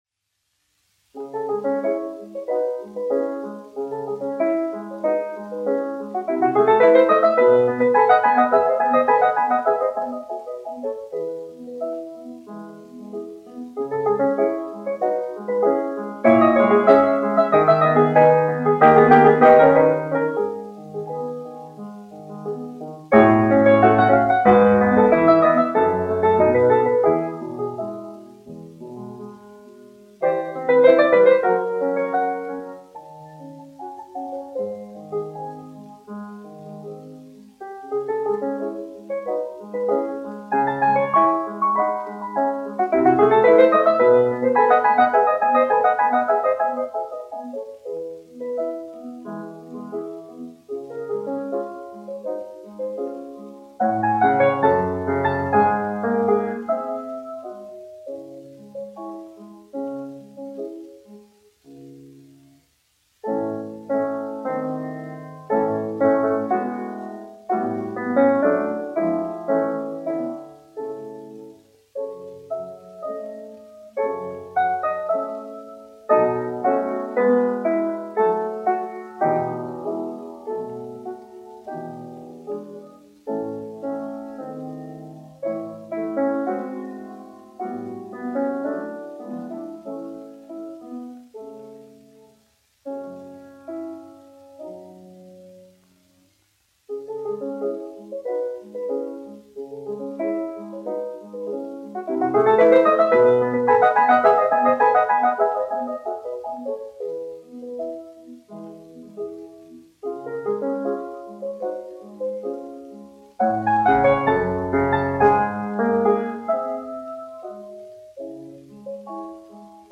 1 skpl. : analogs, 78 apgr/min, mono ; 25 cm
Klavieru mūzika
Latvijas vēsturiskie šellaka skaņuplašu ieraksti (Kolekcija)